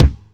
Index of /kb6/Akai_XR-20/Kick